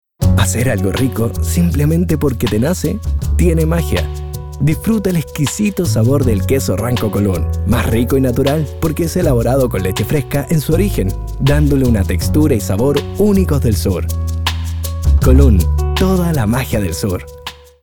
Dubbing Actor - Voiceover - Narrator - Voiceacting - and more
chilenisch
Sprechprobe: Werbung (Muttersprache):